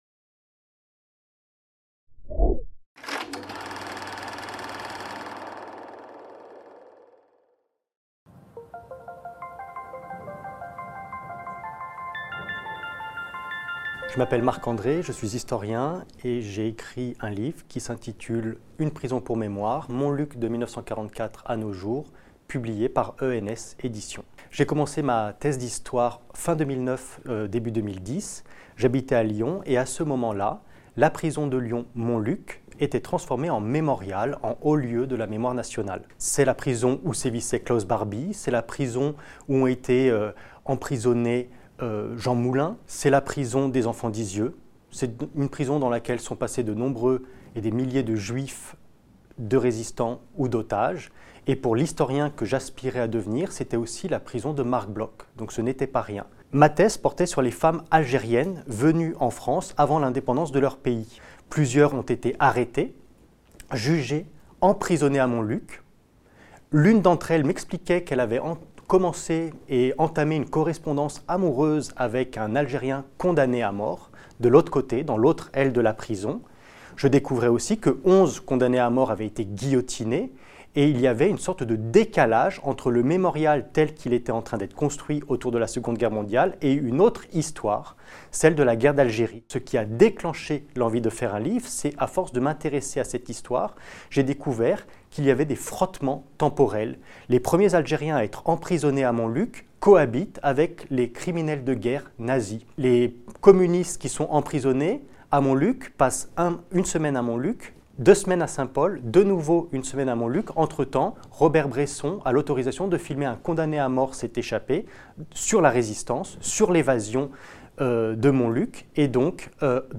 Une prison pour mémoire. Montluc, de 1944 à nos jours - Interview